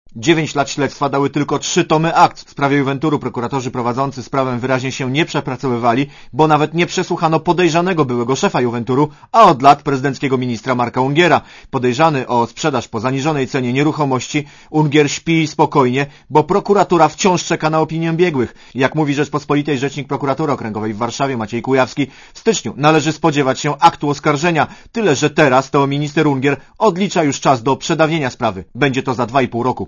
Relacja reportera Radia ZET Ungierem interesuje się też Prokuratura w Katowicach, która sprawdza, czy prezydencki minister ujawnił tajemnicę państwową.